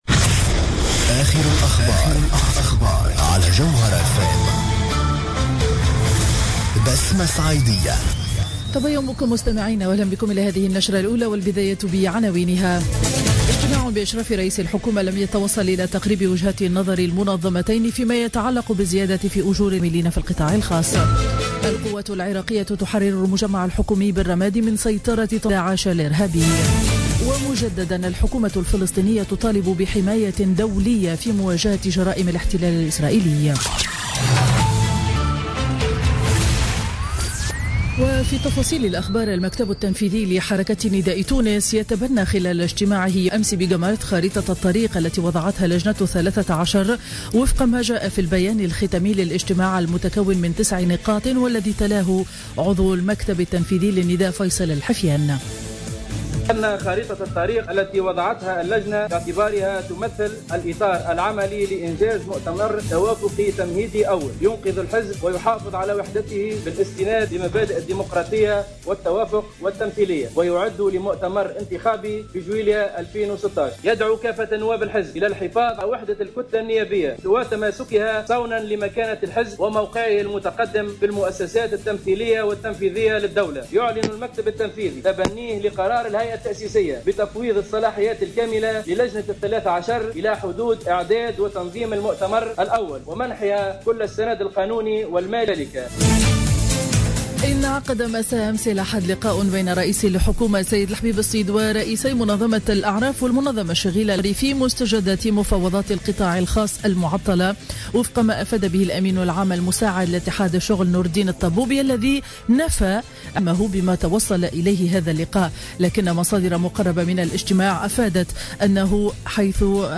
نشرة أخبار السابعة صباحا ليوم الاثنين 28 ديسمبر 2015